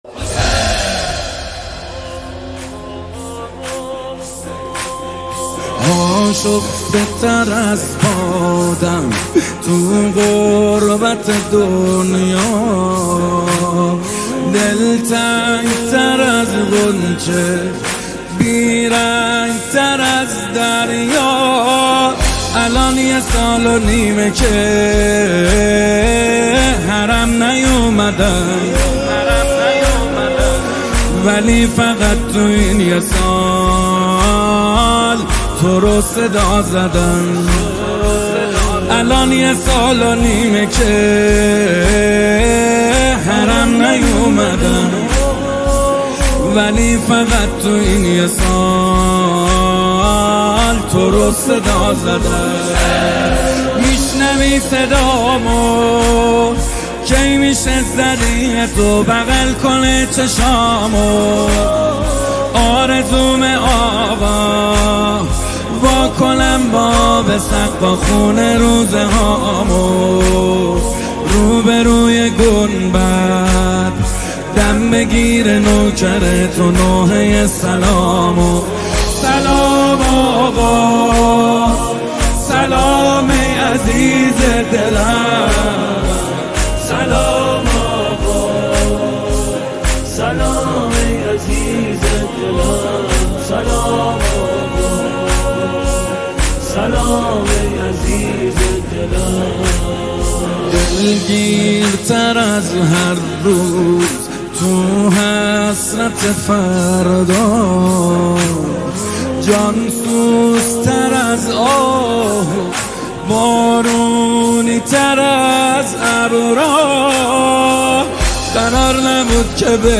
نماهنگ دلنشین
مداحی اربعین